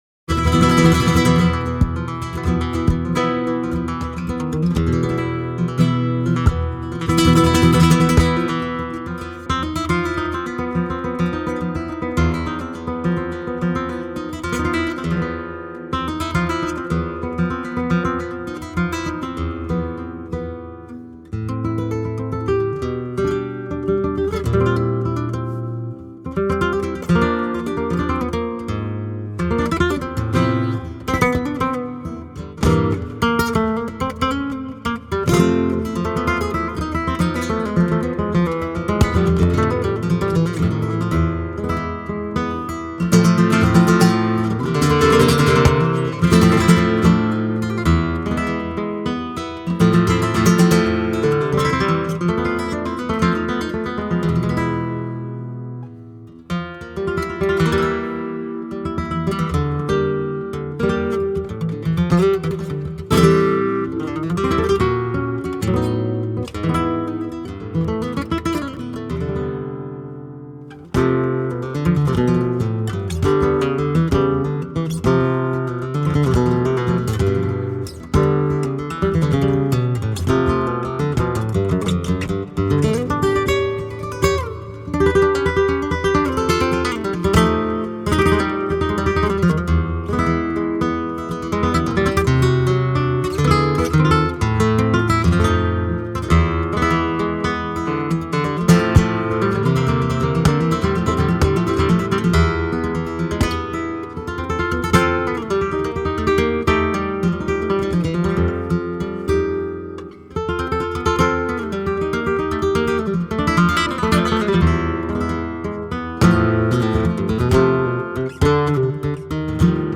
composition et guitare